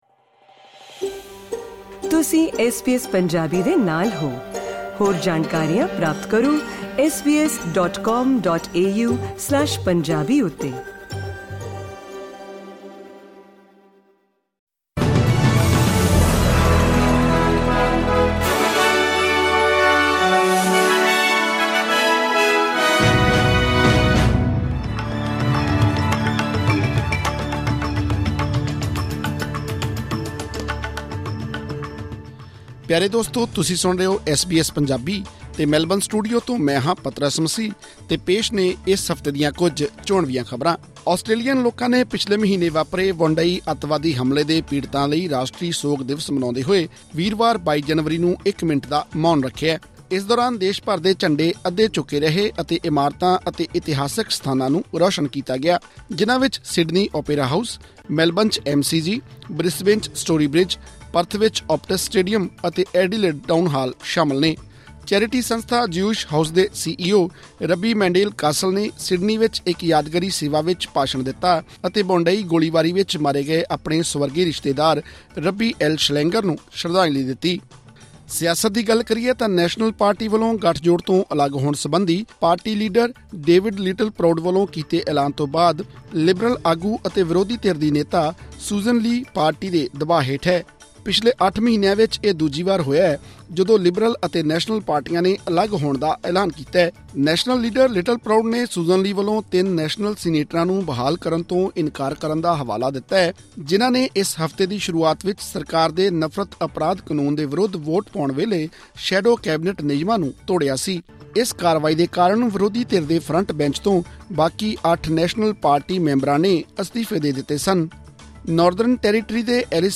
Top news updates of the week in Punjabi.